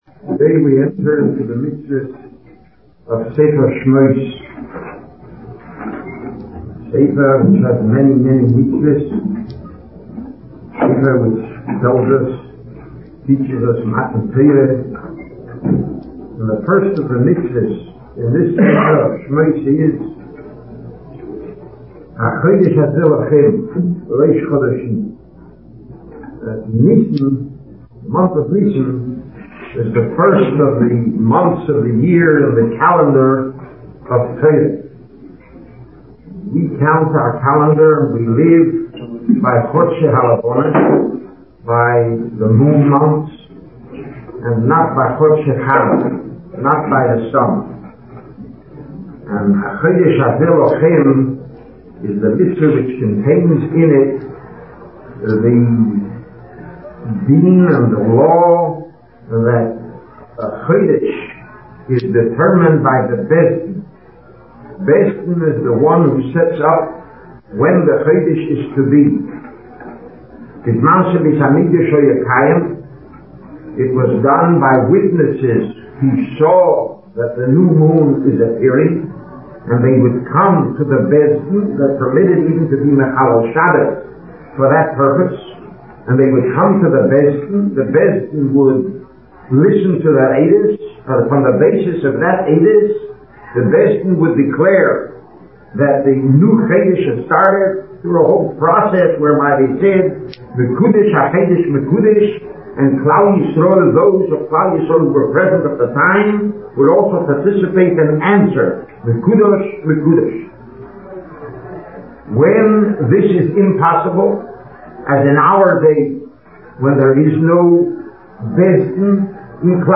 Rav Gifter speaking about Parshas Shemos on the Mitzvah of Rosh Chodesh.
Recent Sermons